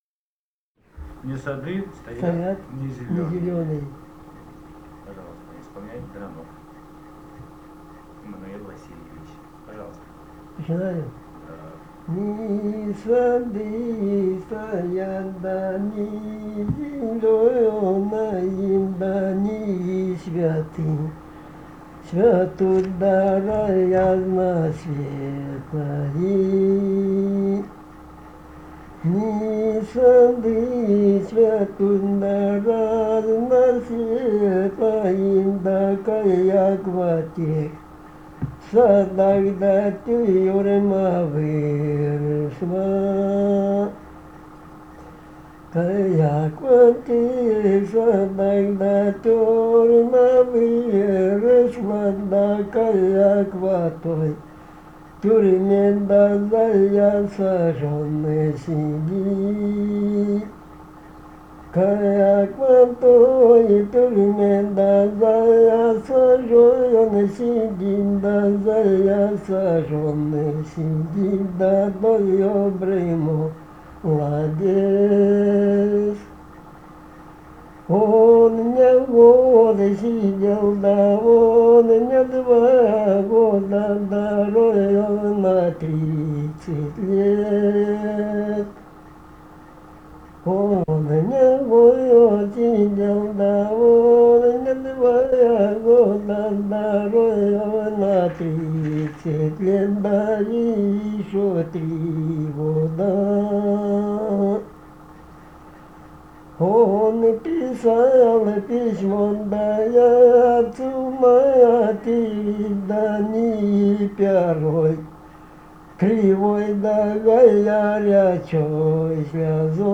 Этномузыкологические исследования и полевые материалы